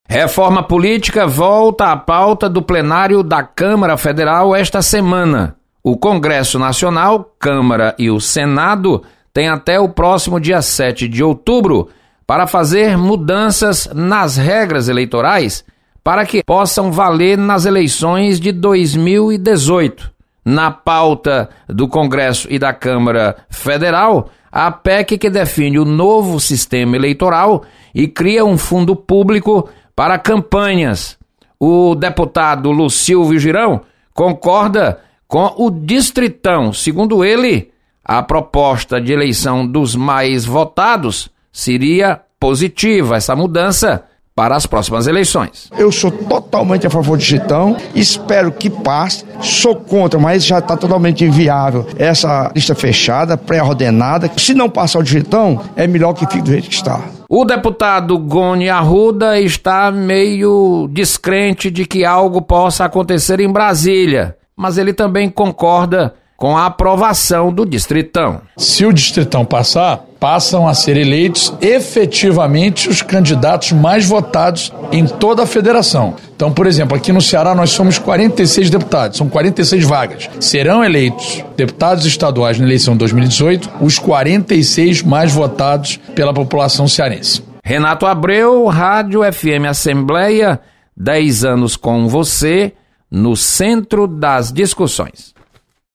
Deputados comentam sobre possível aprovação do Distritão.